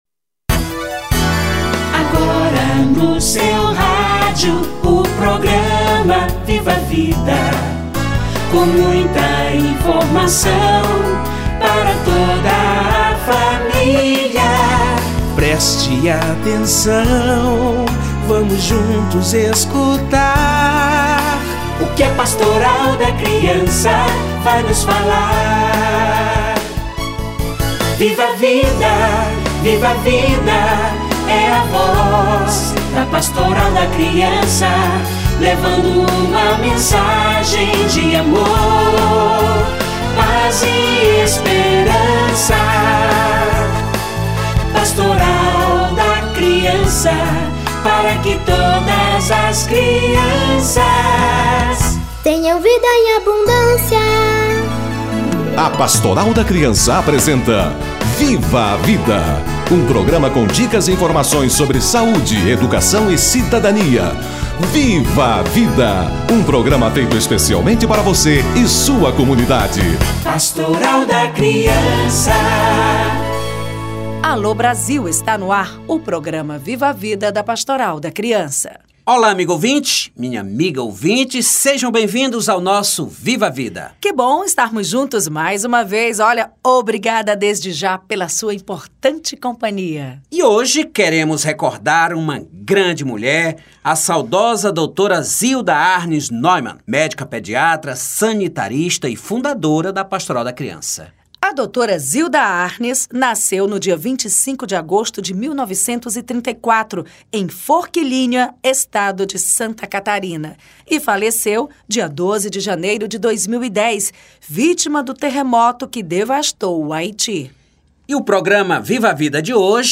Dra Zilda: o exemplo que continua - Entrevista